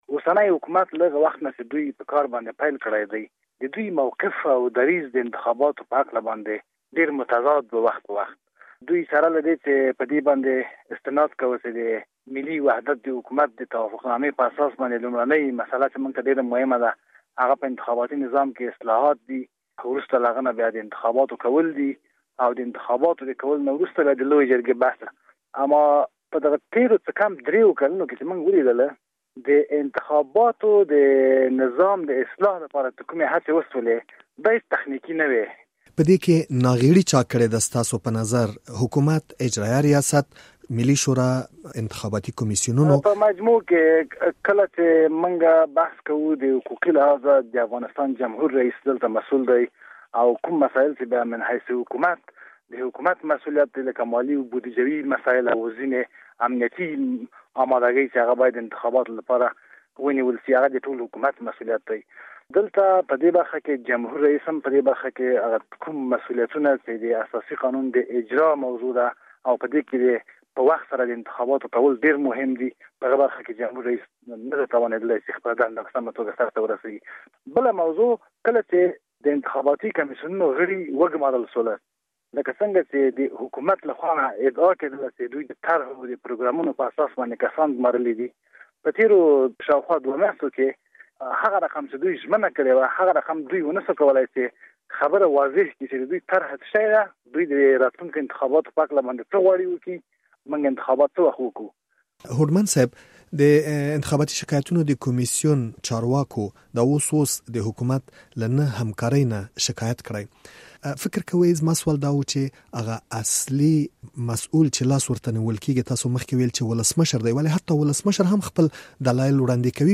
مرکه